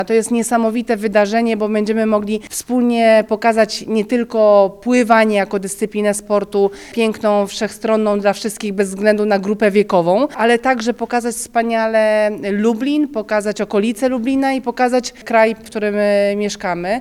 – To impreza o nieco innym charakterze – przyznaje prezes Polskiego Związku Pływackiego Otylia Jędrzejczak.